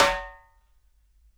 SNARE RINGY.wav